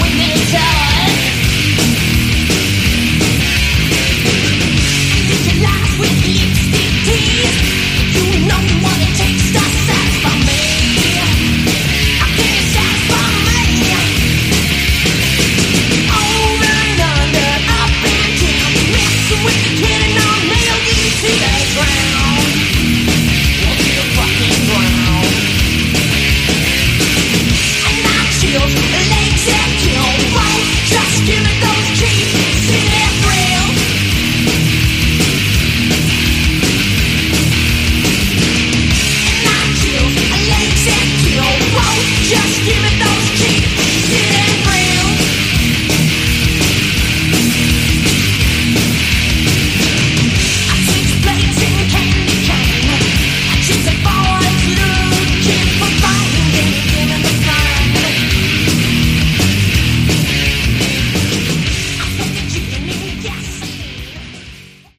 Category: Sleaze Glam
lead vocals
guitars
drums
bass
Recorded in 1991.